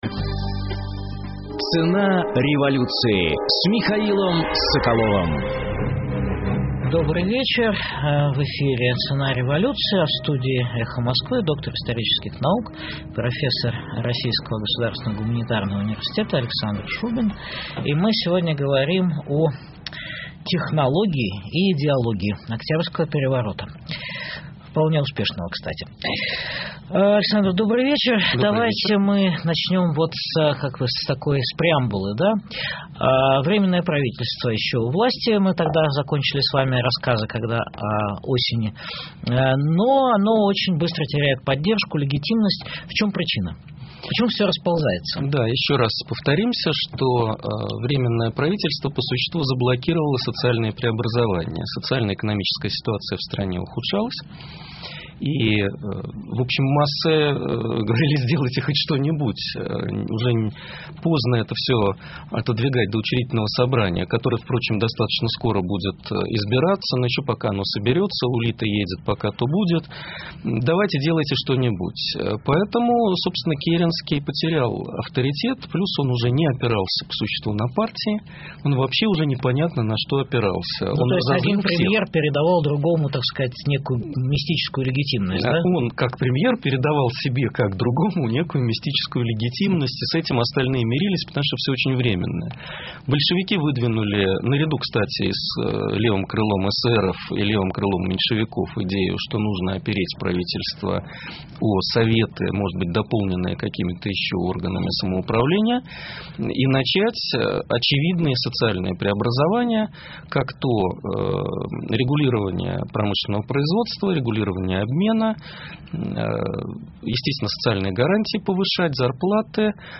В студии «Эха Москвы»